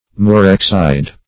Murexide \Mu*rex"ide\, n. [L. murex the purple fish, purple.]